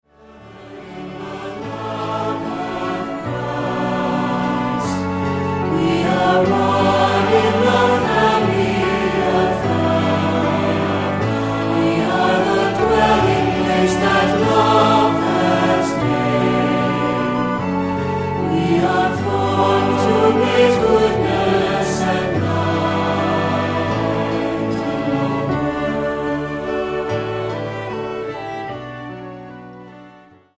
Voicing: Cantor; Assembly